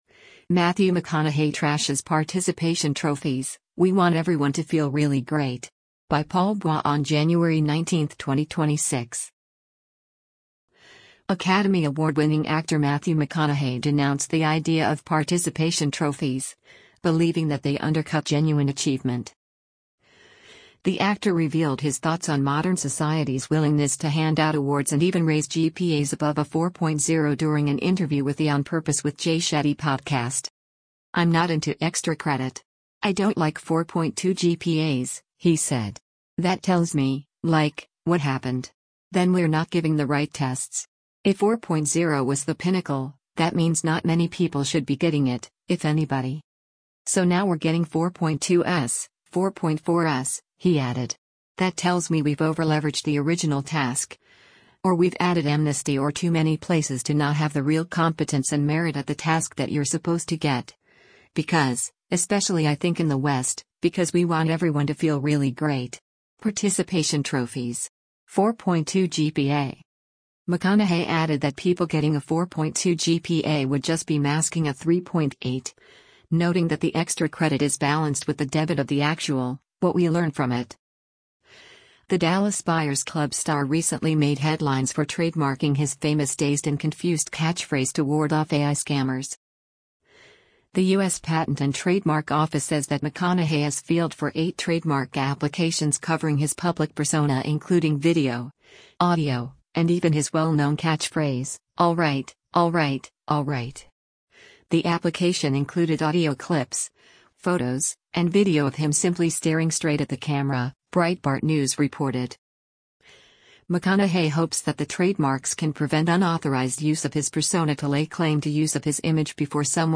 The actor revealed his thoughts on modern society’s willingness to hand out awards and even raise GPAs above a 4.0 during an interview  with the On Purpose with Jay Shetty podcast.